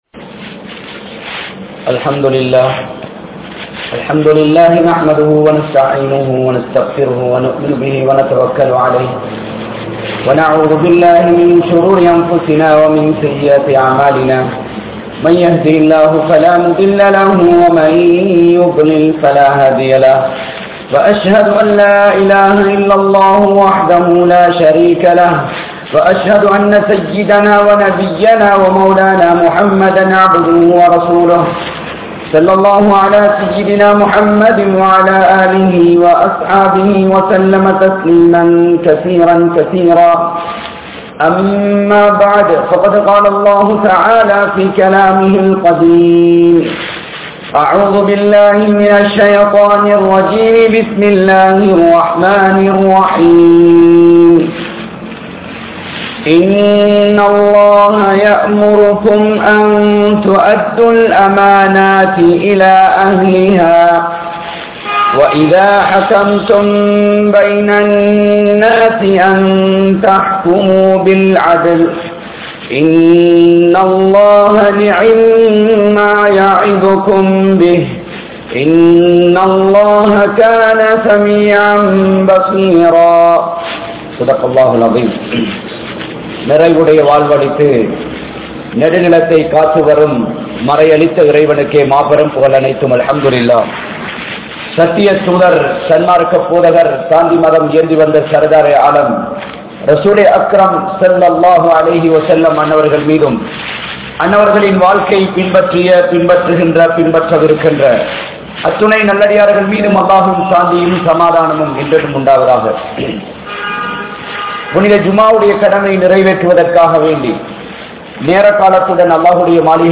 Amaanitham (அமானிதம்) | Audio Bayans | All Ceylon Muslim Youth Community | Addalaichenai